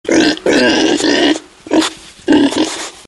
Категория: Звуки